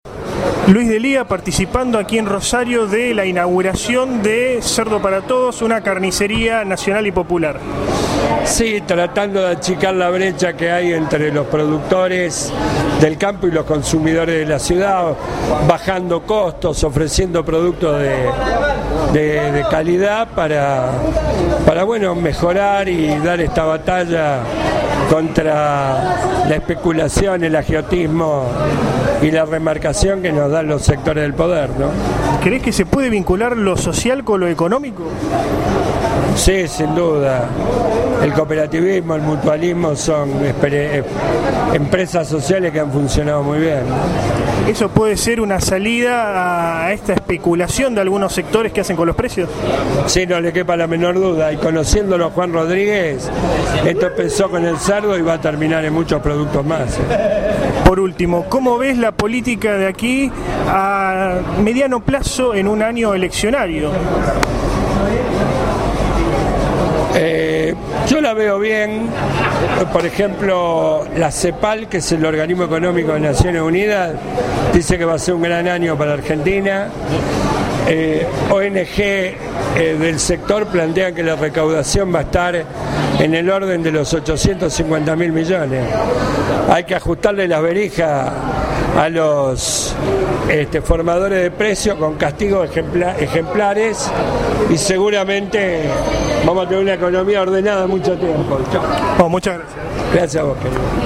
AUDIO ENTREVISTA LUIS D’ELÍA